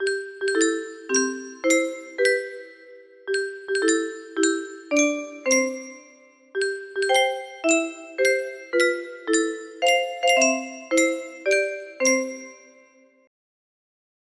Happy Birthday 90 music box melody
Simple Happy birthday with 90 beat length